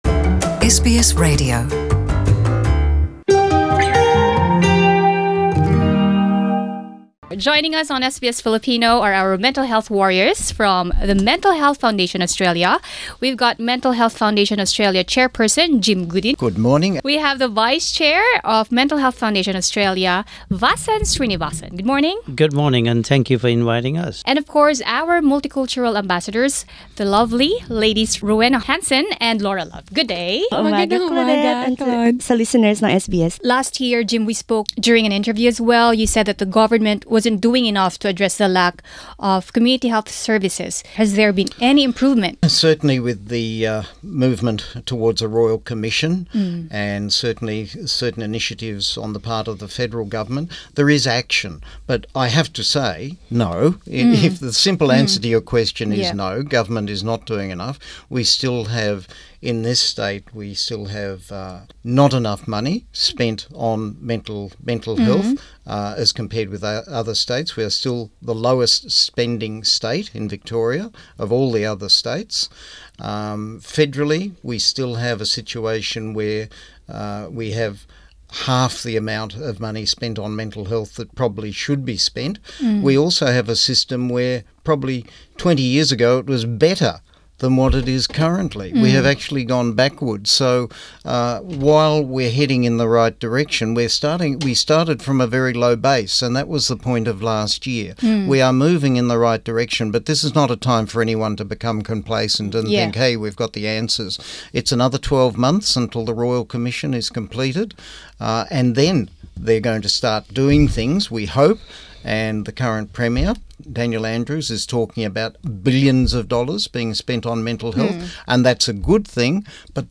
Ating kinausap ang mga mental health warriors mula Mental Health Australia kaugnay ng kahalagahan ng kaalaman sa komunidad at paghatid ng suporta sa mga taong nakakaranas ng mental illness